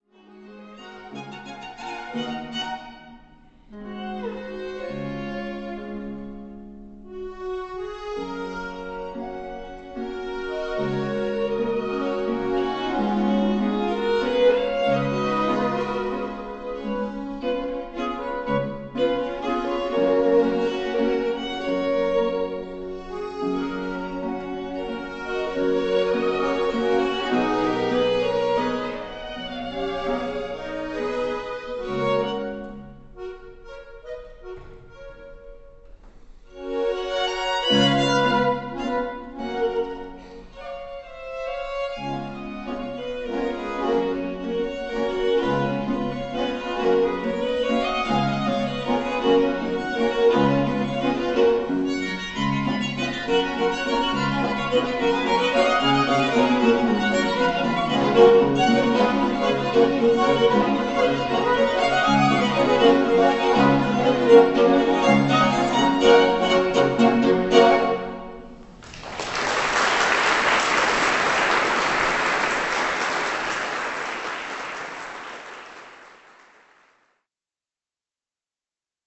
** Quartett mit Knopfharmonika
Aufgenommen live am 13.5.2007,
Markus Sittikus-Saal, Hohenems